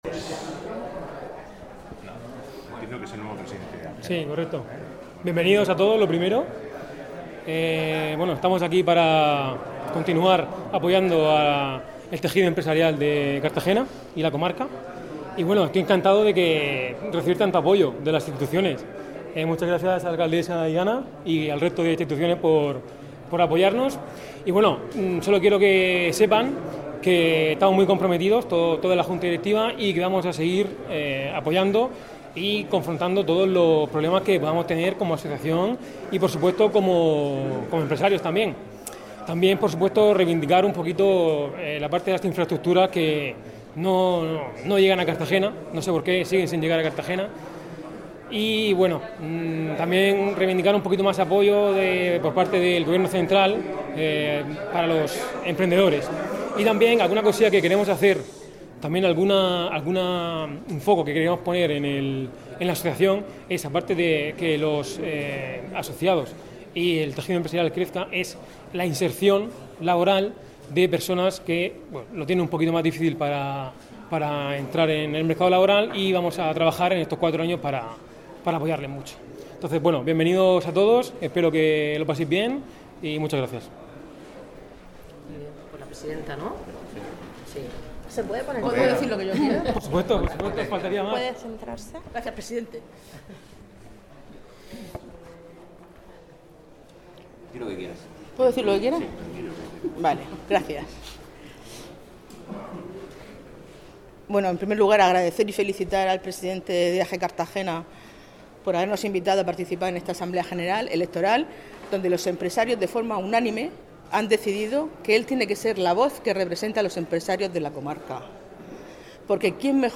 Declaraciones
tras la asamblea de la AJE